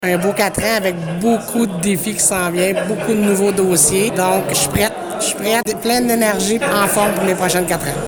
C’est mardi, à l’Auberge du Draveur, que se déroulait la cérémonie d’assermentation de la préfète réélue, Chantal Lamarche. Après son premier mandat de 4 ans, la préfète de la Vallée-de-la-Gatineau s’est dite heureuse de la confiance que lui a accordée la population en l’élisant pour un second mandat :